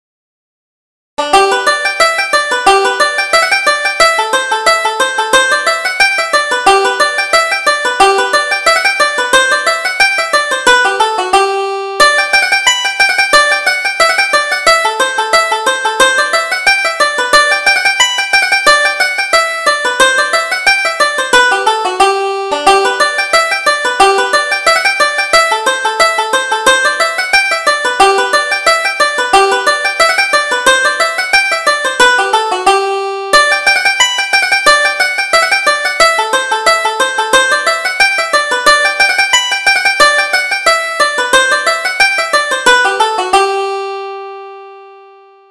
Reel: The Women's Rock